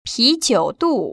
[píjiŭdù] 피지우뚜